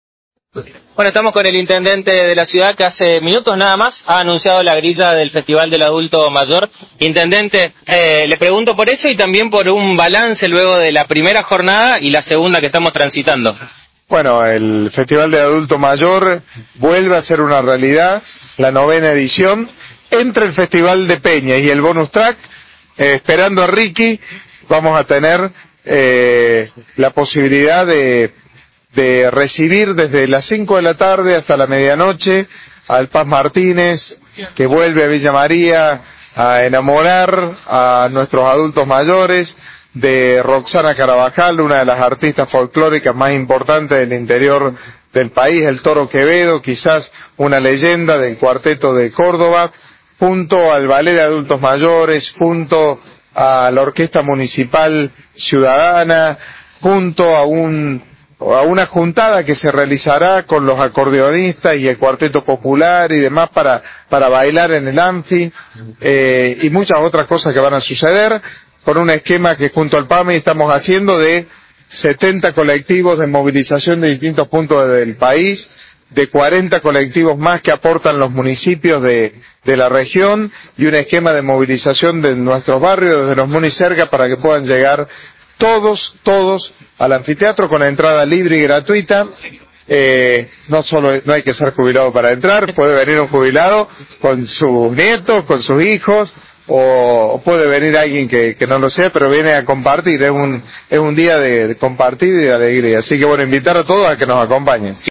En una conferencia de prensa, el intendente Martín Gill destacó la importancia de este tipo de eventos para la ciudad y resaltó su permanencia en el tiempo. Además, el mandatario local informó que el PAMI pondrá a disposición 70 colectivos para el traslado gratuito de los adultos mayores desde todos los puntos de la Provincia, sumándose a las 40 unidades aportadas por municipios y comunidades de la región.